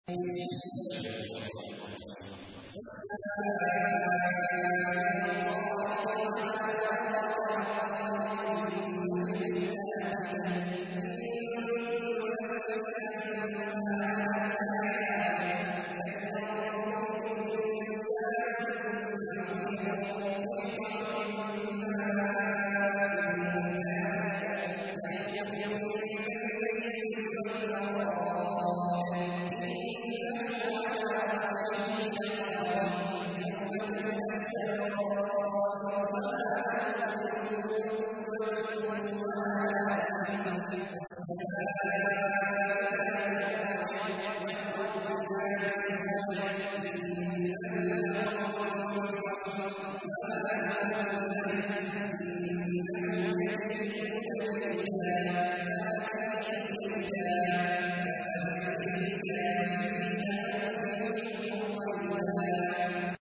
تحميل : 86. سورة الطارق / القارئ عبد الله عواد الجهني / القرآن الكريم / موقع يا حسين